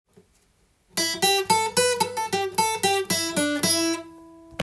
Aのブルースでも弾けるようにkeyを変換してみました.
９ｔｈと言われるマイナーペンタトニックスケール外の音が使われているので
おしゃれな感じがしてカッコいいですね。